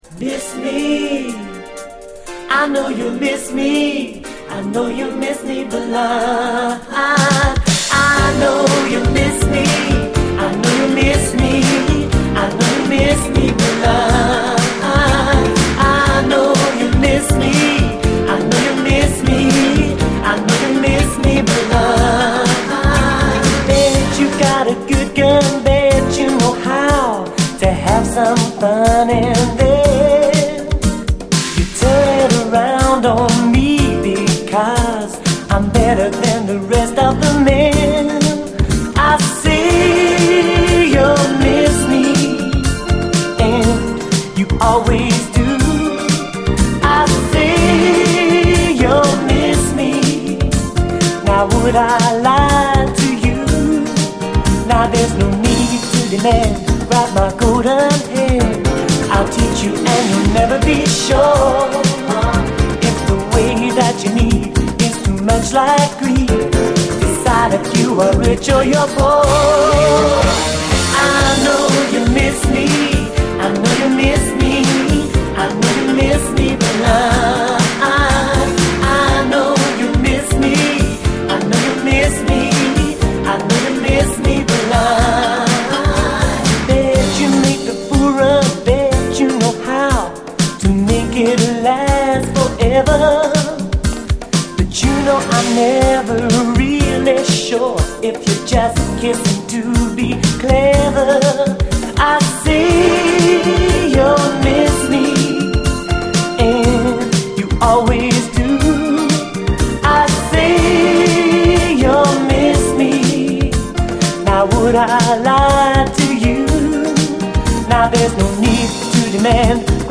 DISCO, 80's～ ROCK, REGGAE, ROCK, FUNK-A-LATINA, 7INCH
ファンカラティーナ的な爽やかさのニューウェーヴ・ヒット